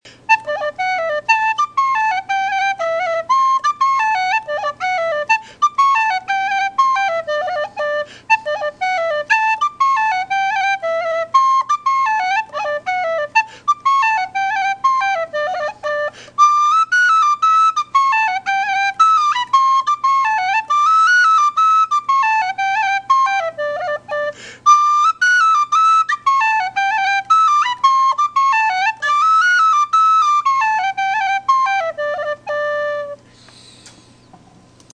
Whistle Reviewed: Weston Whistle in Cherry Wood
Construction: Wood with Wooden Fipple plug
Volume: Slightly above average.
Responsiveness: Quick.
Tuning: In tune along it’s range.